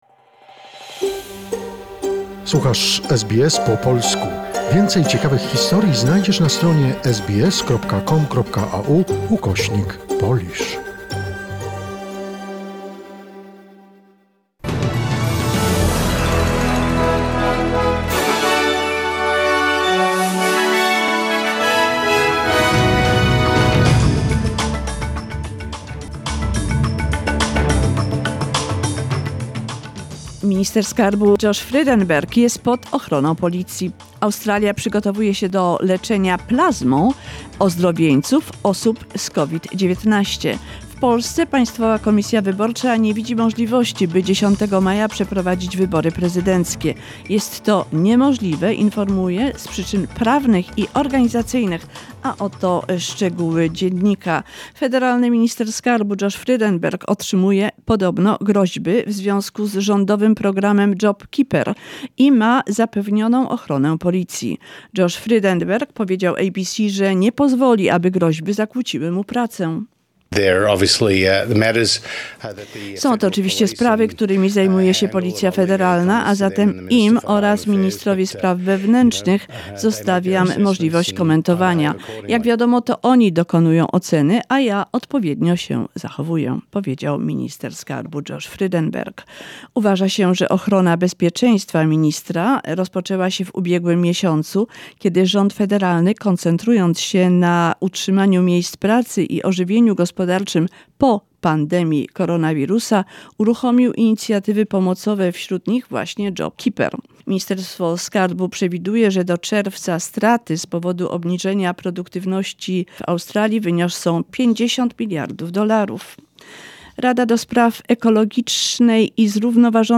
SBS News, 6 May 2020